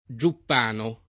giuppano [ J upp # no ]